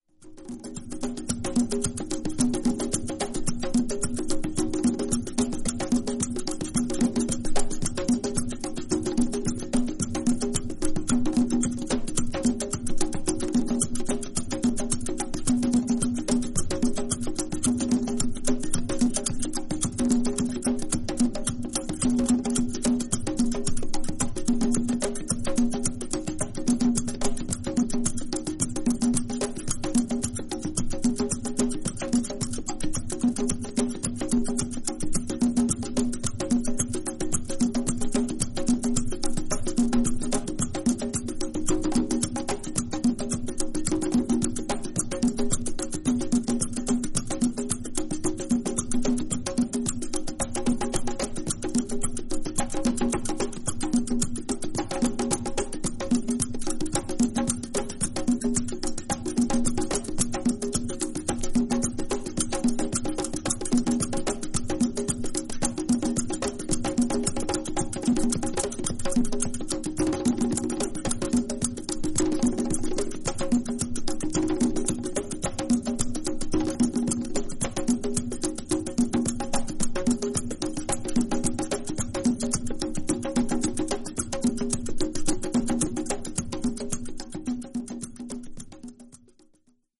プリミティブでトライバルなリズムの洪水に、ある種のトランス状態へと誘われる、DJ〜トラック・メイカー必須のアルバムです。